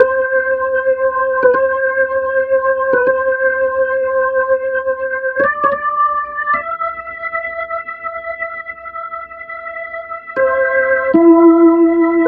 Track 07 - Organ 01.wav